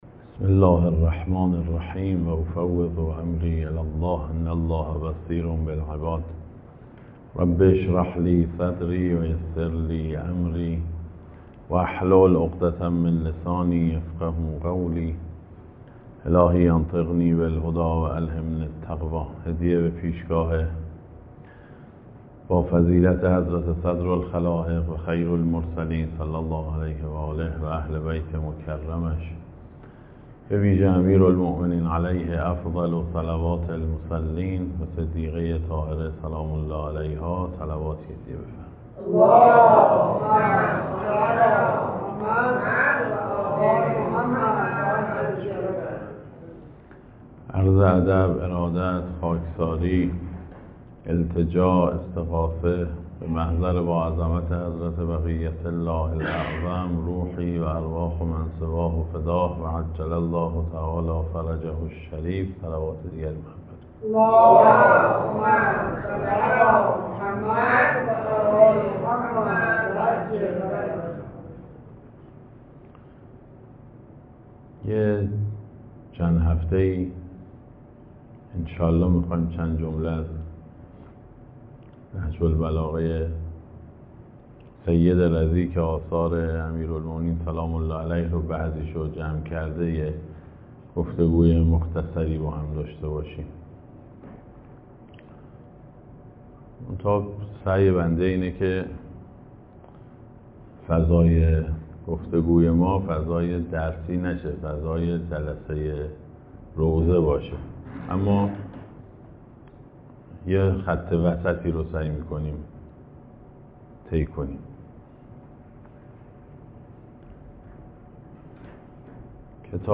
اشتراک گذاری دسته: آشنایی با نهج البلاغه , امیرالمومنین علیه السلام , سخنرانی ها قبلی قبلی تفاخر به حضرت زهرا سلام الله علیها – دو نکته راجع به یک نگاه بعدی در مواجهه با علائم ظهور جوگیر نباشیم بعدی